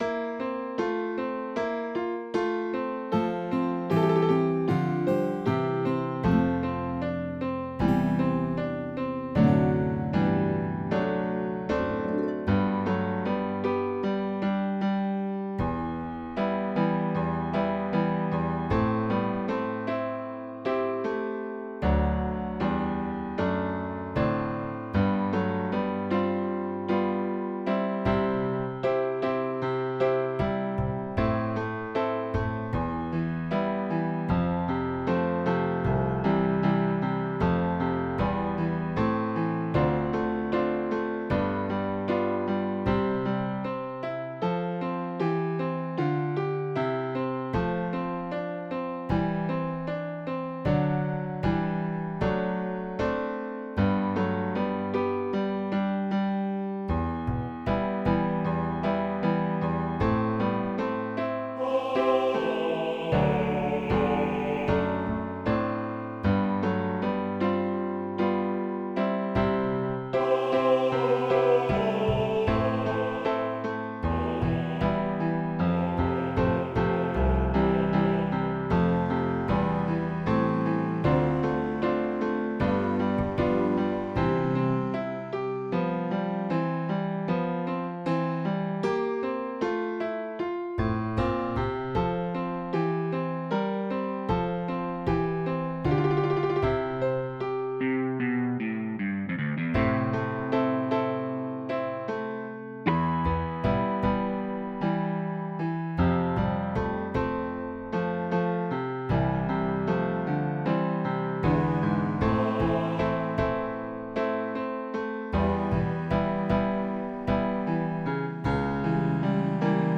base senza melodia